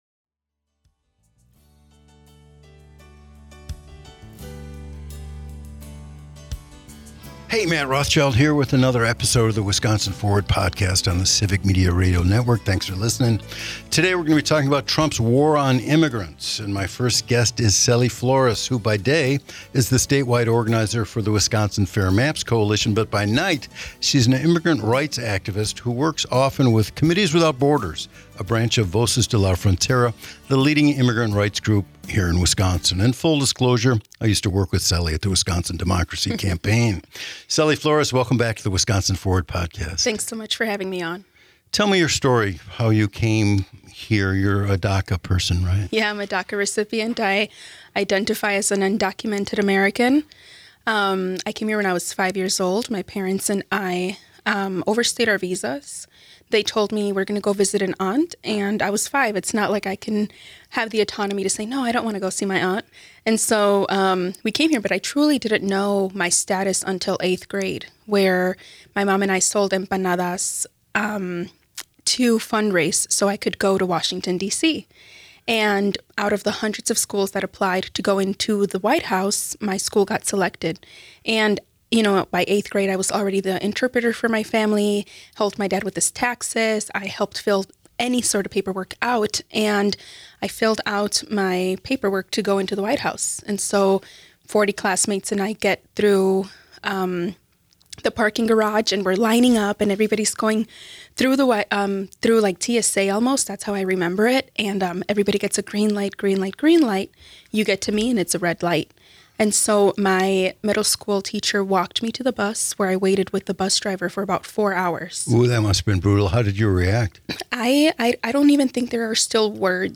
This multipart series will take a deeper look into Wisconsin politics, featuring voices from around the state and the Civic Media network, to provide the context of our complex swing state and provide some insight on how the state can move forward.